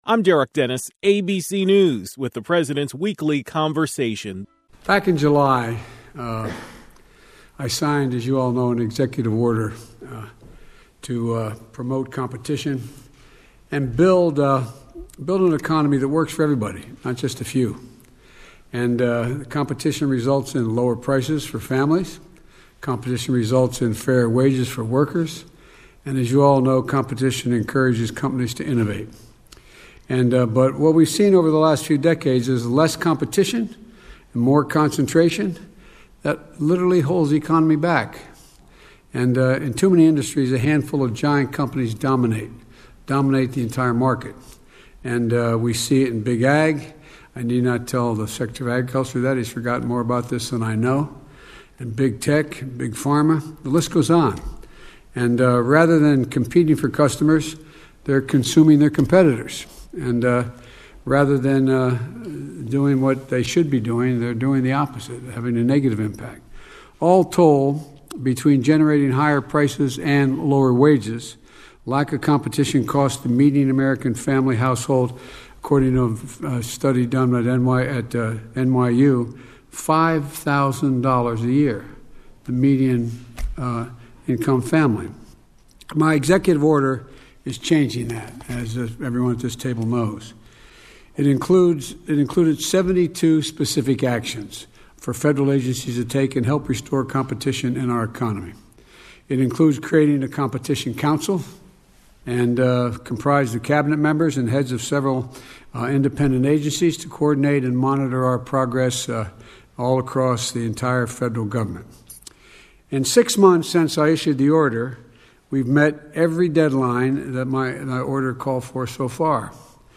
President Biden delivered remarks before meeting with the White House Competition Council.